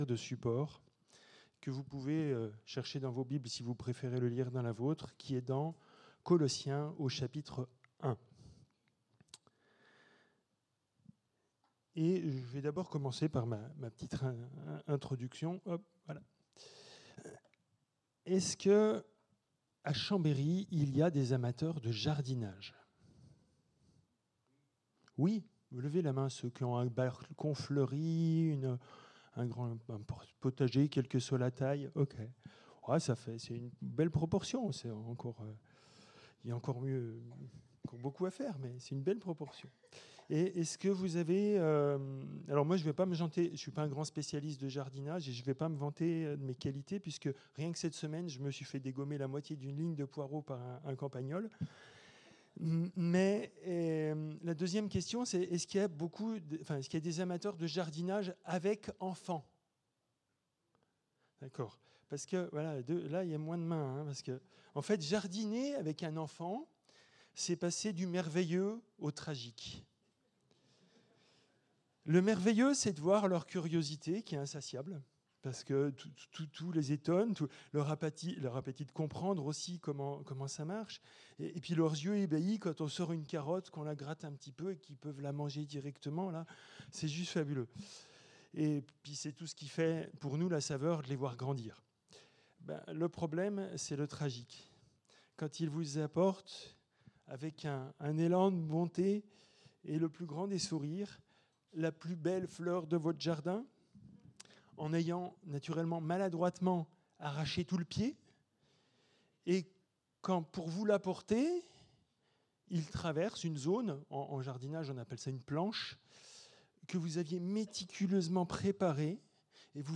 Culte du 09 fév.